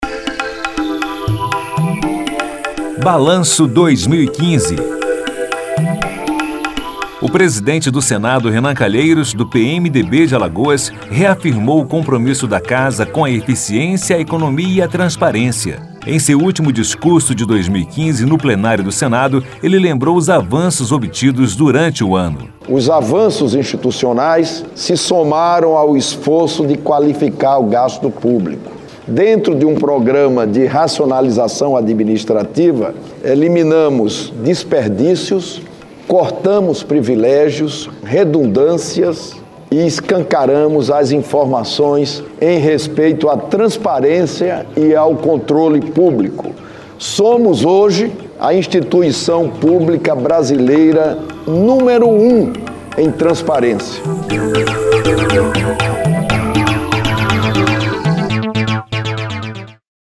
O presidente do Senado, Renan Calheiros, reafirmou o compromisso da Casa com a eficiência, a economia e a transparência. Em seu último discurso de 2015 no Plenário do Senado, ele lembrou os avanços obtidos durante o ano.